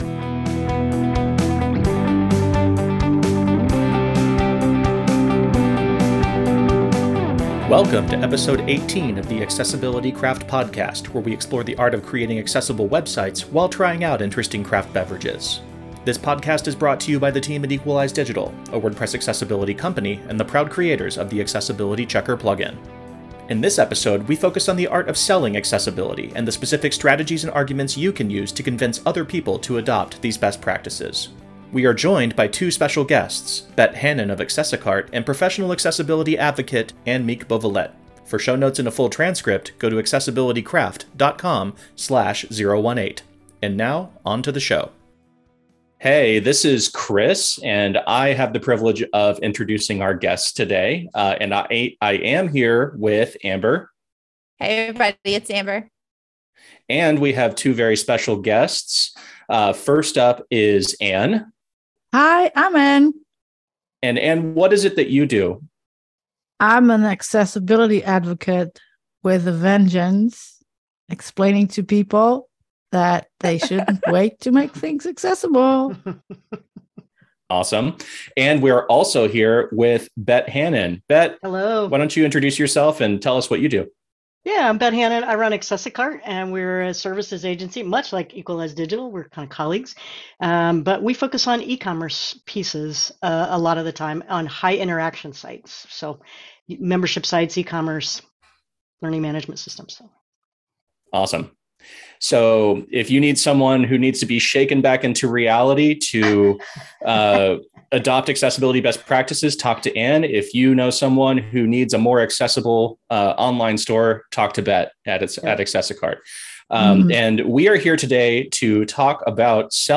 We are joined by two special guests